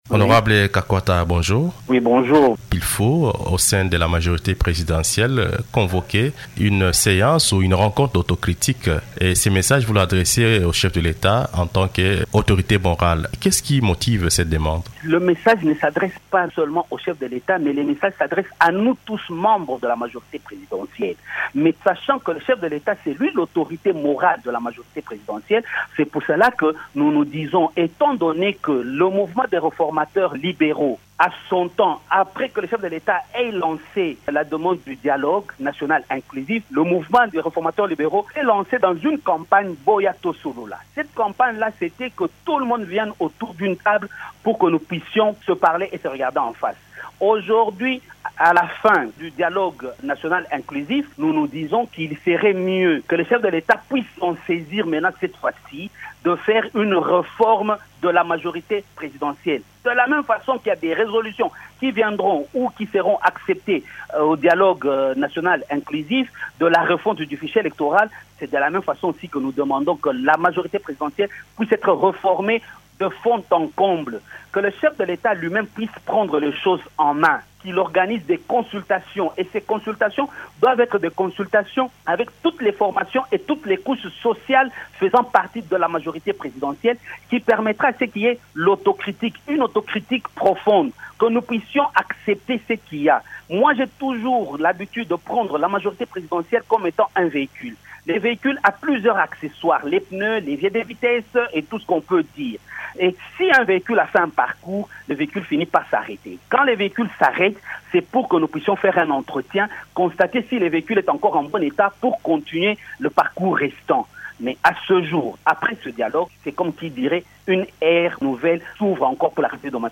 Invité de Radio Okapi ce vendredi, André Kakwata justifie sa proposition par la nécessité pour les membres de  cette plate-forme politique de réfléchir sur les enjeux de l’heure et les stratégies à mettre en place pour que ce regroupement politique soit plus efficace.